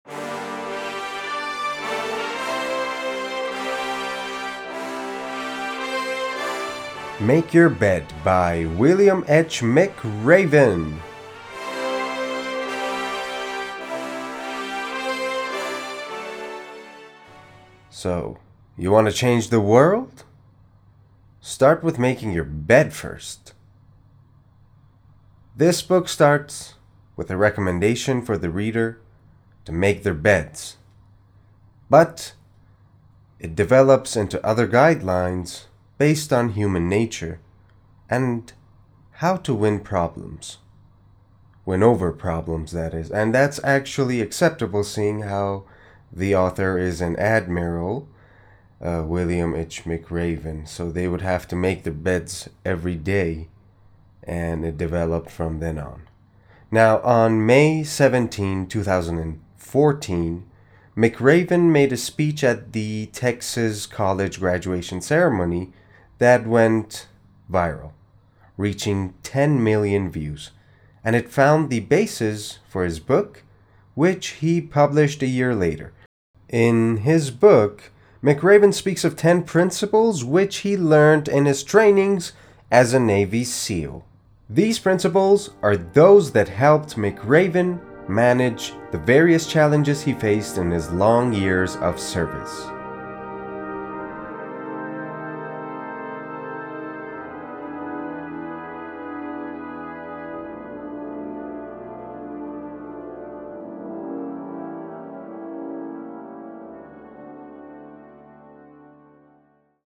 معرفی صوتی کتاب Make Your Bed